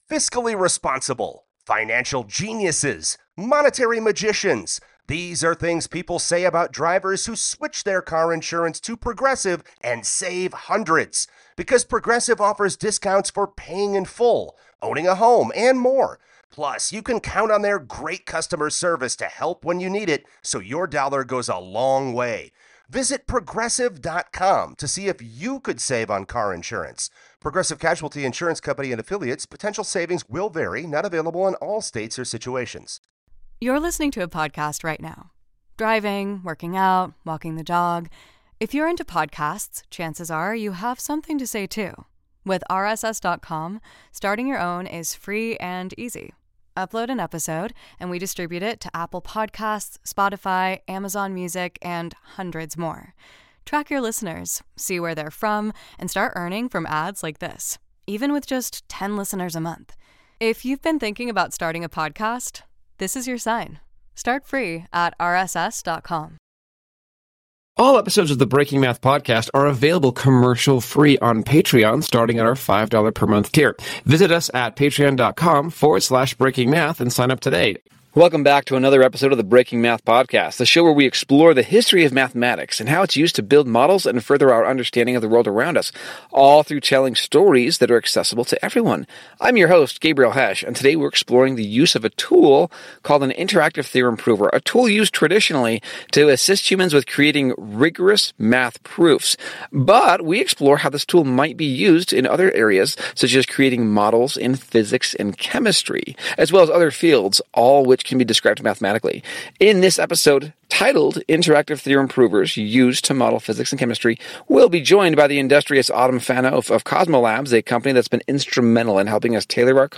In this episode the hosts review a paper about how the Lean Interactive Theorem Prover, which is usually used as a tool in creating mathemtics proofs, can be used to create rigorous and robust models in physics and chemistry.